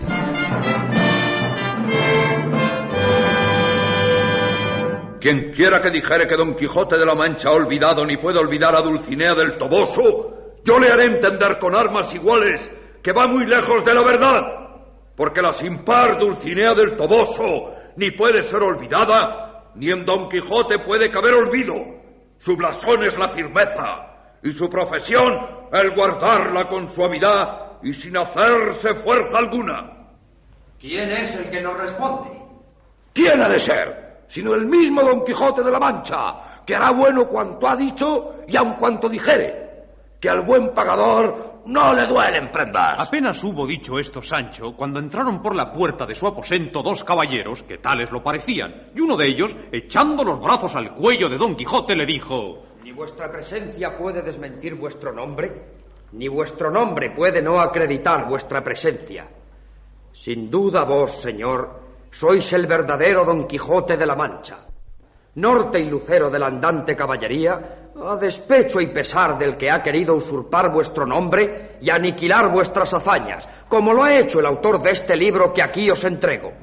Fragment de l'adaptació radiofònica del Quijote de Cervantes en el que parla Sancho Panza.
Ficció